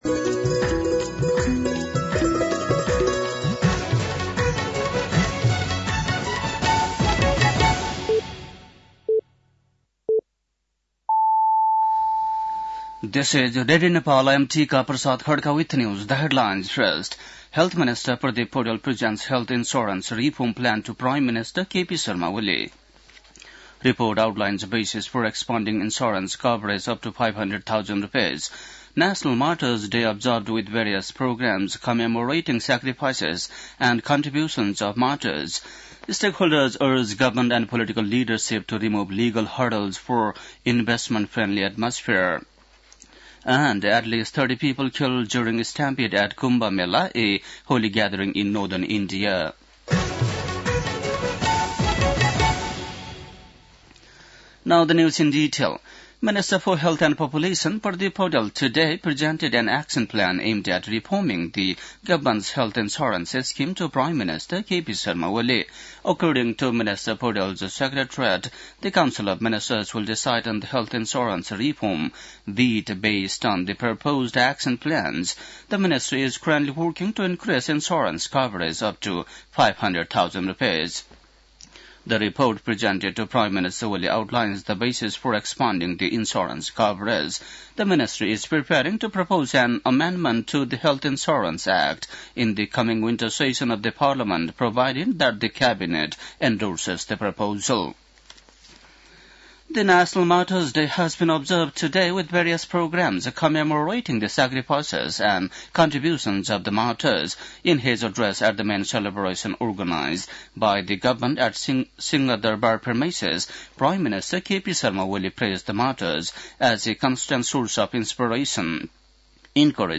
बेलुकी ८ बजेको अङ्ग्रेजी समाचार : १७ माघ , २०८१
8-PM-English-News-10-16.mp3